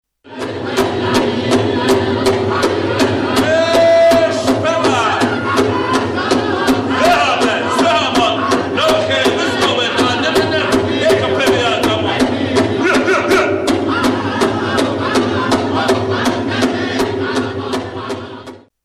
Pow Wow Albuquerque 2008
Extraits sonores enregistrés en direct sur place
Danses et incantations
Encore les tambours, mais cette fois le chef de cérémonie cite les tribus arrivant sur la piste, paroles ponctuées du célébre cri guttural des indiens.